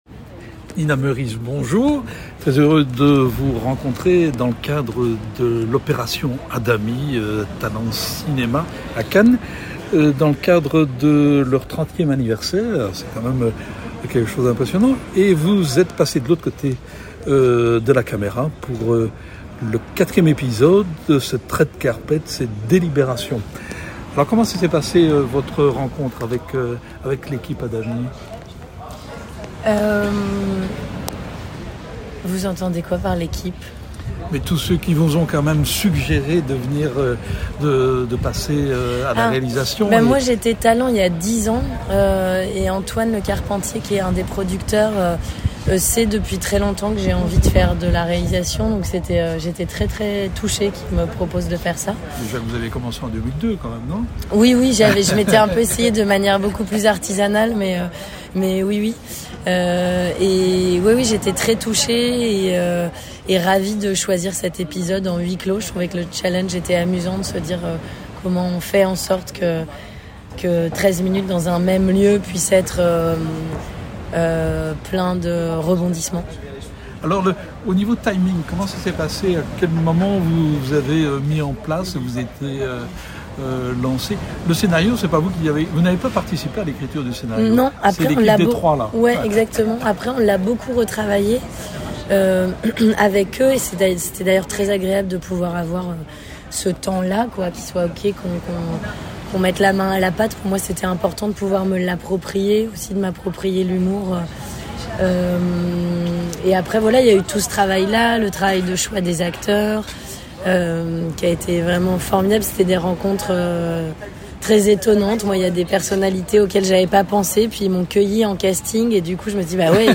Rencontre avec une passionnée qui découvrit très tôt sa voie en tournant son premier film à 10 ans, face à Isabelle Huppert! On y parle aussi de « 37 secondes », la nouvelle série qu’elle tourne actuellement pour HBO.